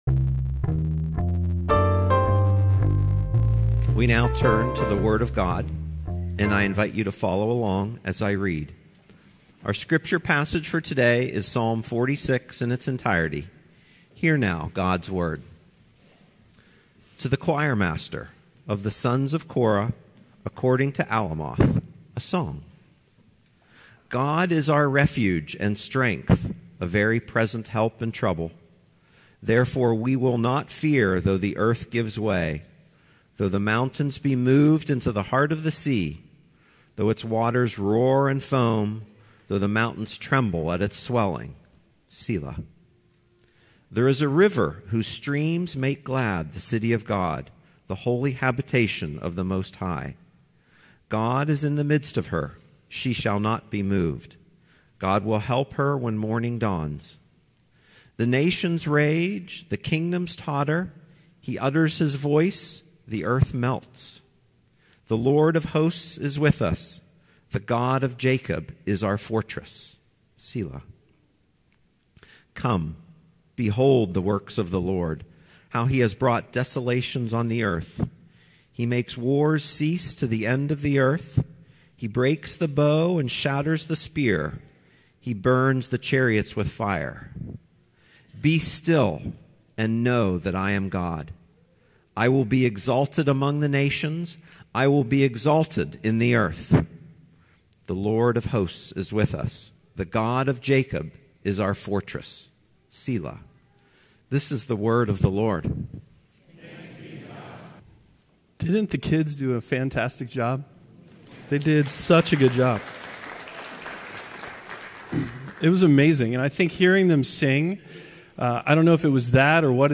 Passage: Psalm 46 Service Type: Sunday Worship